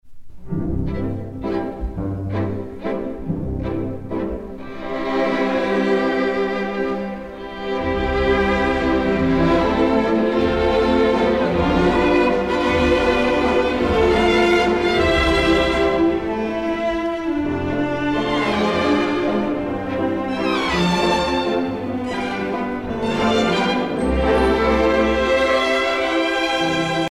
danse : valse viennoise
Pièce musicale éditée